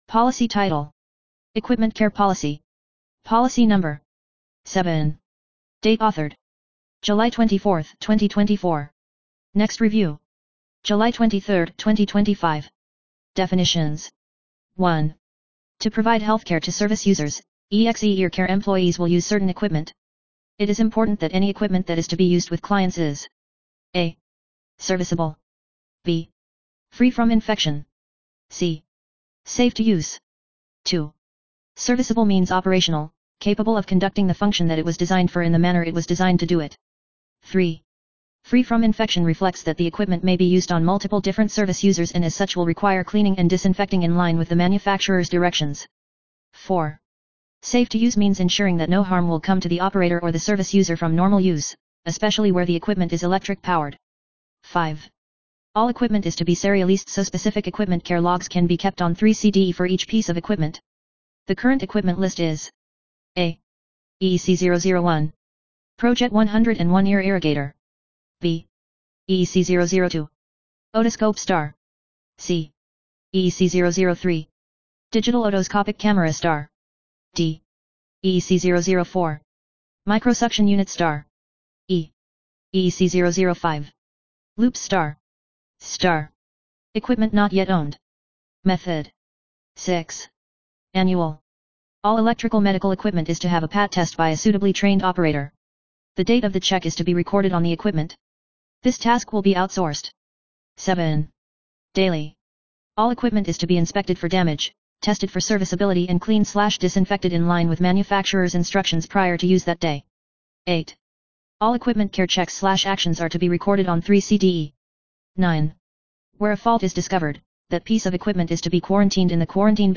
Narration of Equipment Care Policy